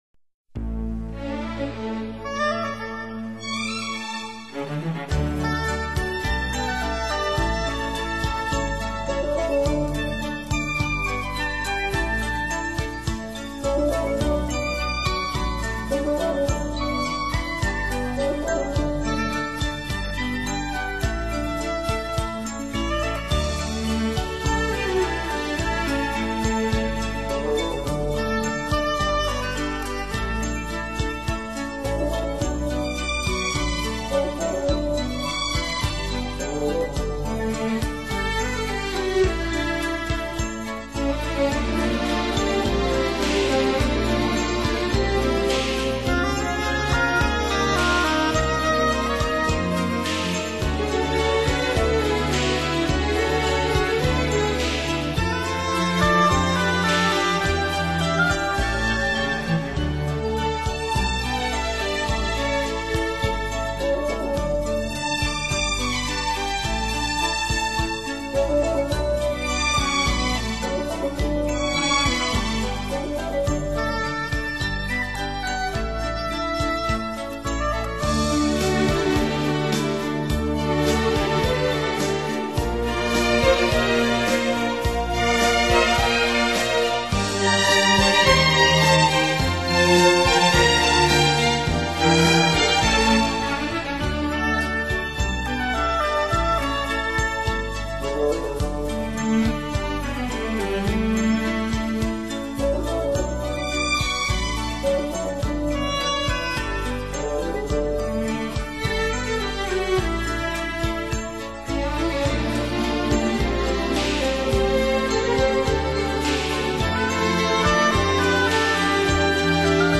Chitarra Basso, Synth）如何在作品当中相互融合的过程。